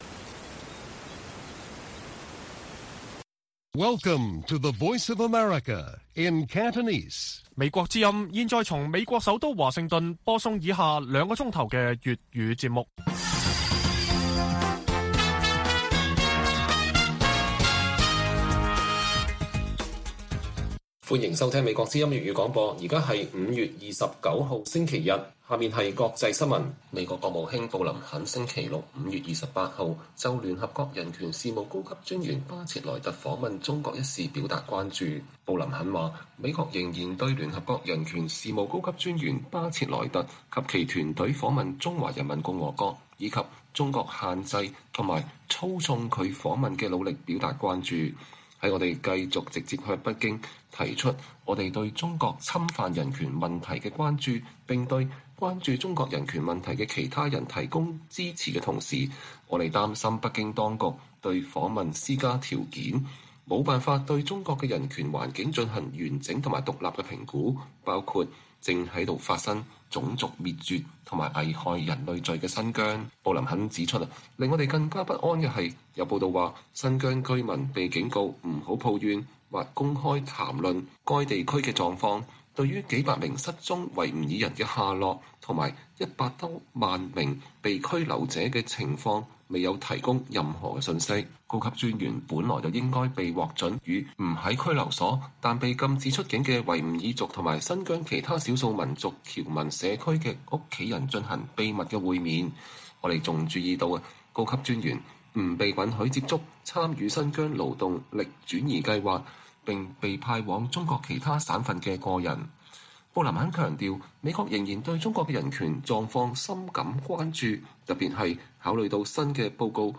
粵語新聞 晚上9-10點 : 逾百英國會議員聯署 要求審查中港官員在英資產